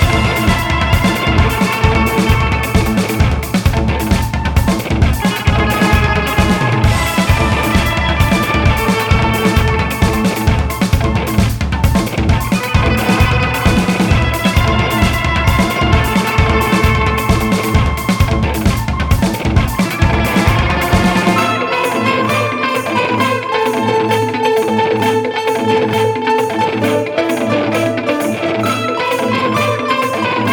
une musique pop aux contours complexes